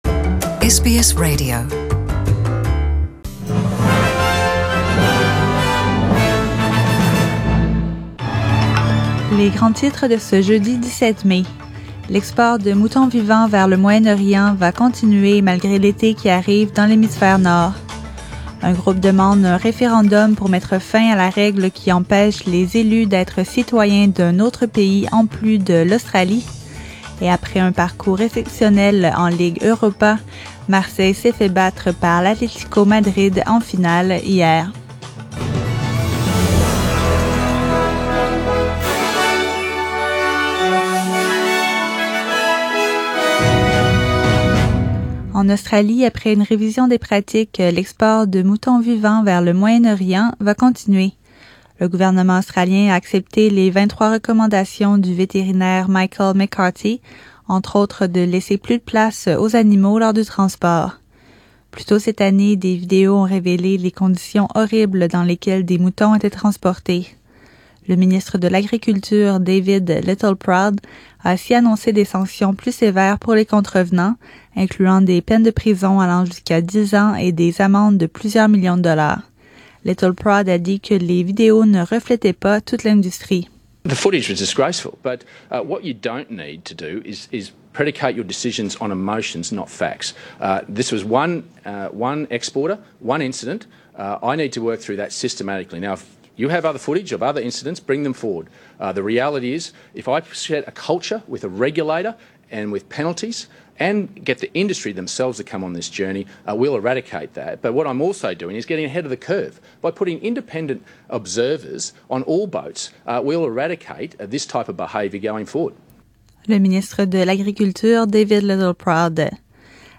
SBS French : Journal du 17/05/2018